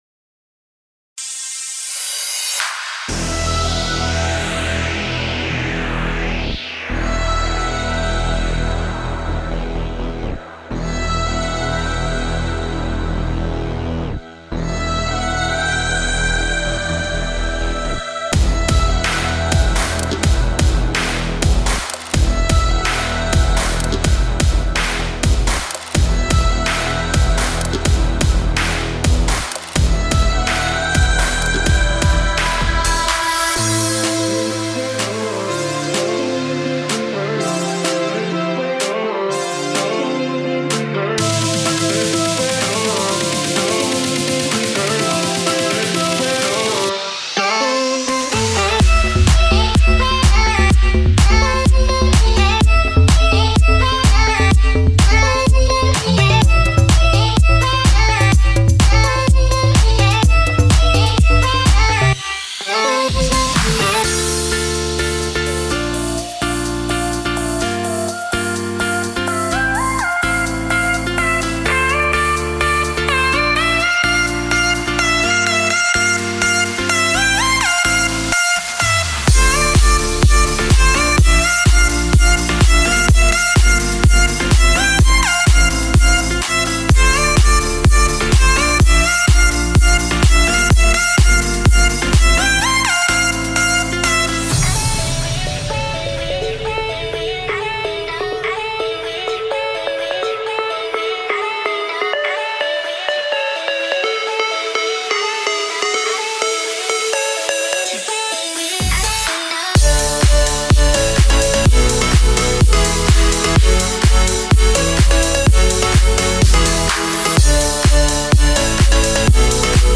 厂牌电音采样包
音色试听